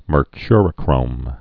(mər-kyrə-krōm)